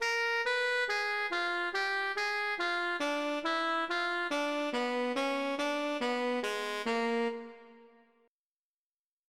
In addition, here are a few public domain diminished scale patterns:
dimished exercise for jazz scales